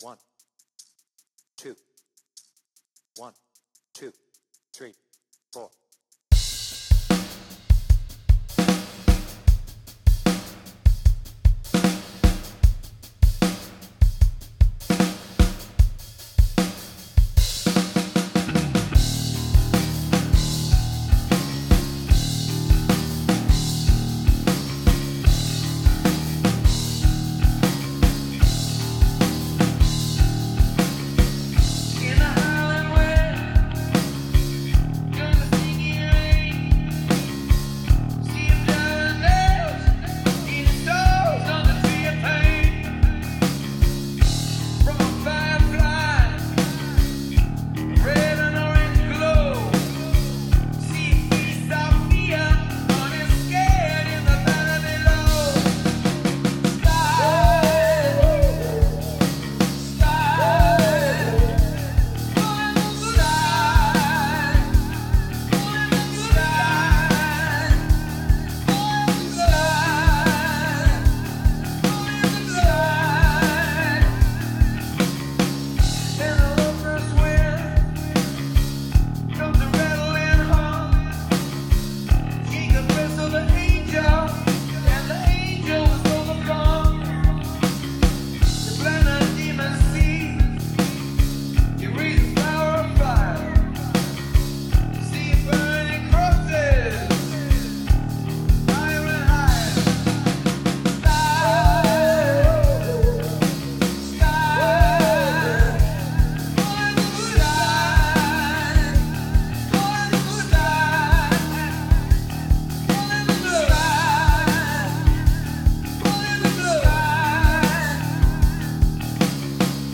BPM : 76
Tuning : Eb